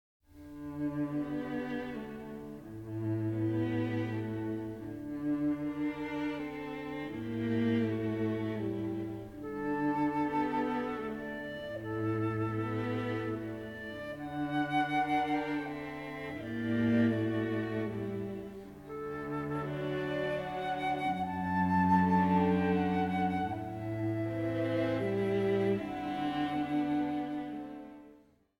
tuneful Americana